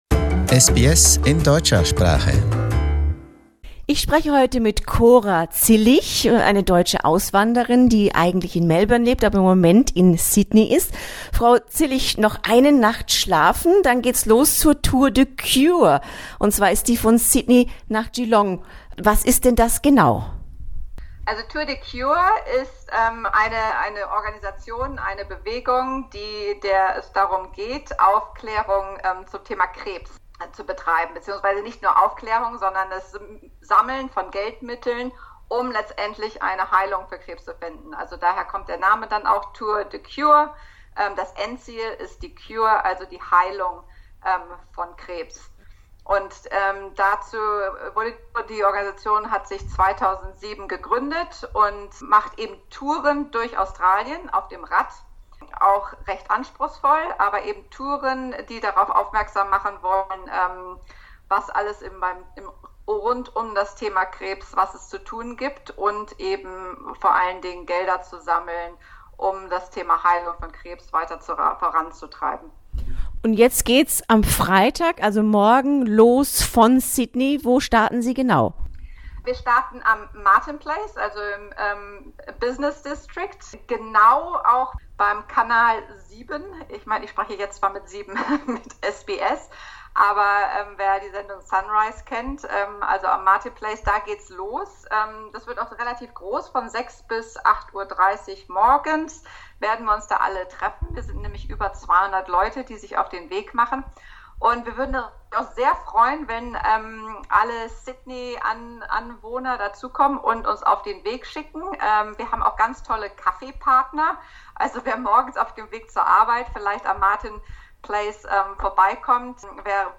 A big event is the Tour de Cure, which starts on Friday in Sydney and aims at furthering cancer research. A German plays an important role.